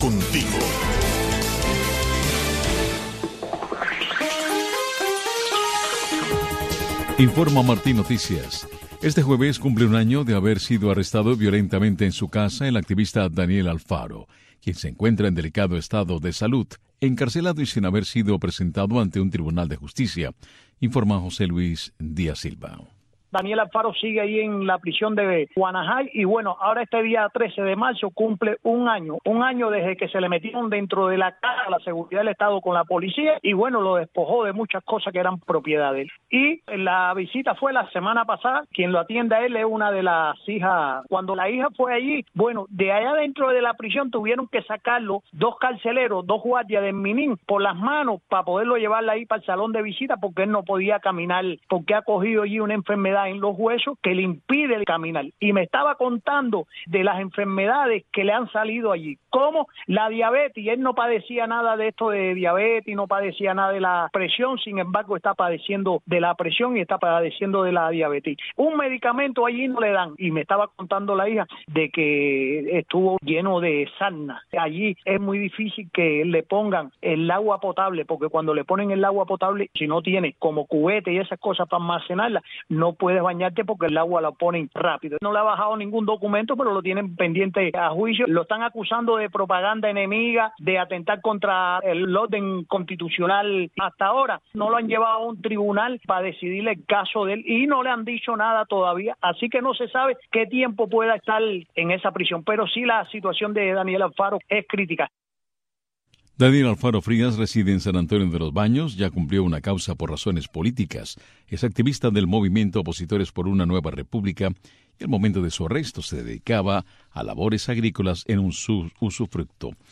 Una mirada a la situación migratoria, para analizar las leyes estadounidenses, conversar con abogados y protagonistas de este andar en busca de libertades y nuevas oportunidades para lograr una migración ordenada y segura.